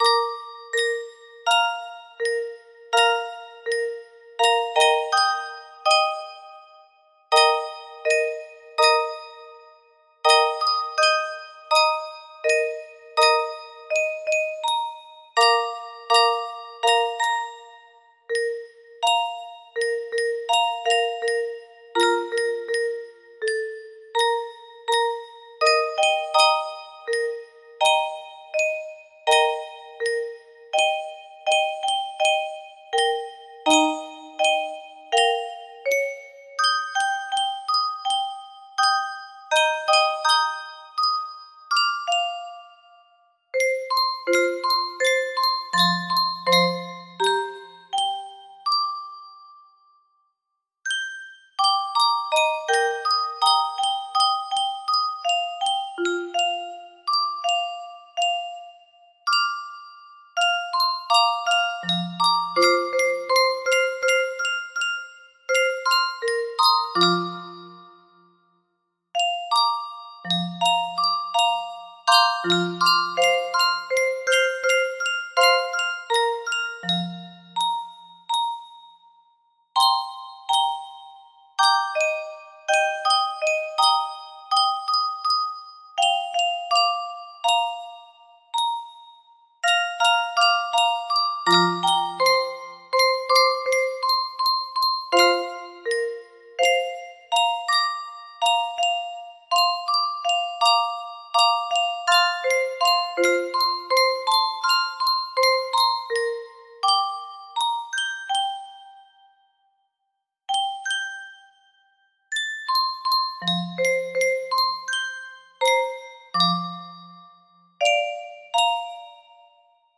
Clone of Unknown Artist - Untitled music box melody